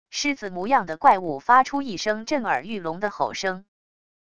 狮子模样的怪物发出一声震耳欲聋的吼声wav音频